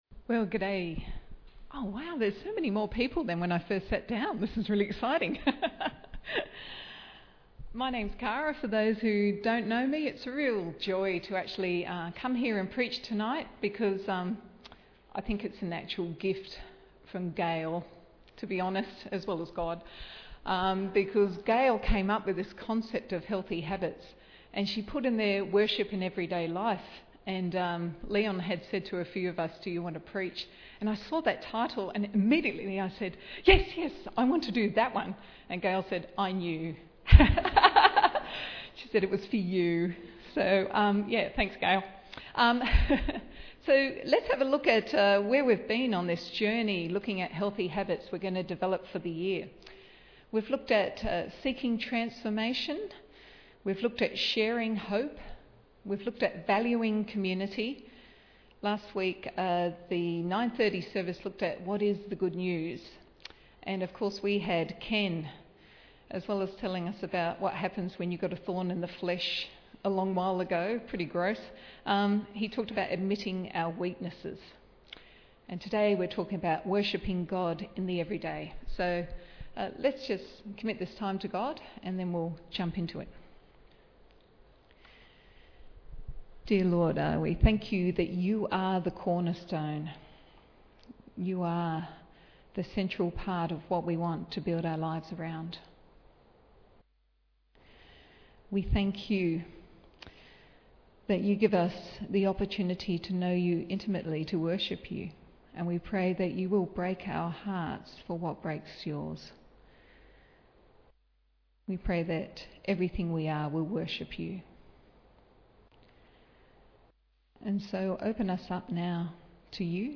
Bible Text: Colossians 3:1-4, 15-25 | Preacher